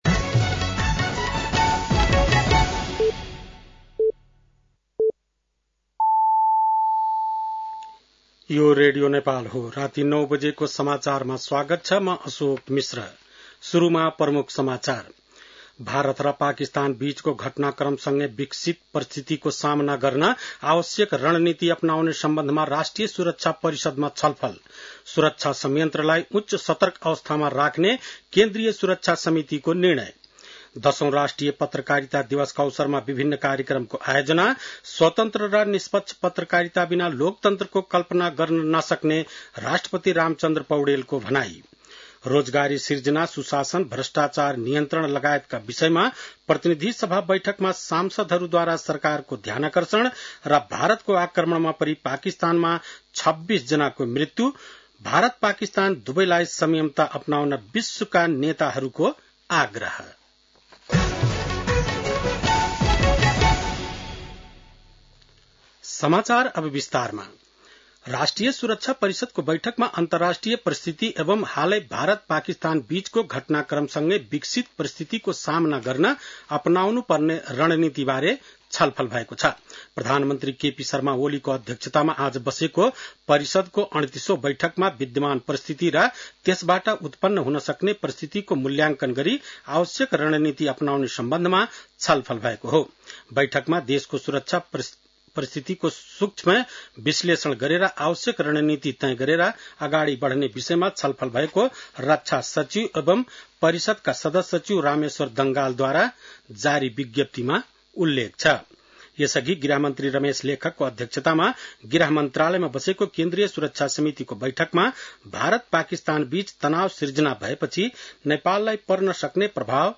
बेलुकी ९ बजेको नेपाली समाचार : २४ वैशाख , २०८२